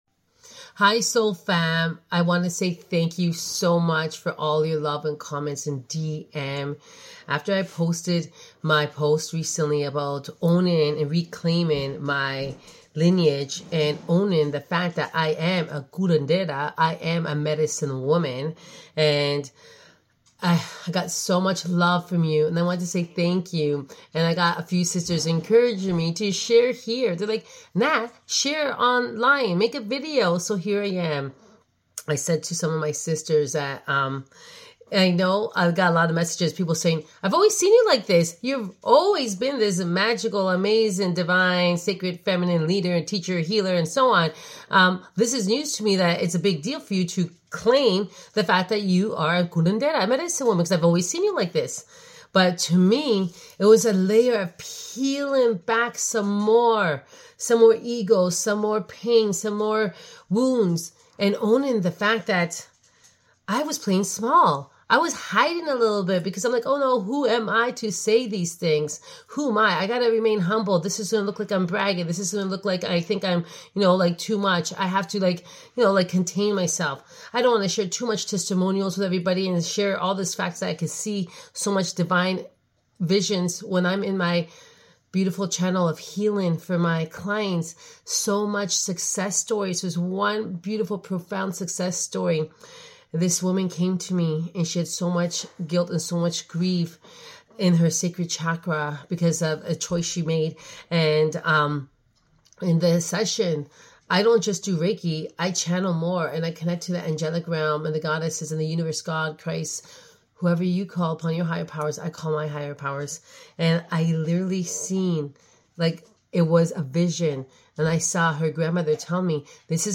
This is a mini episode I recorded in video format and shared on my Instagram platform.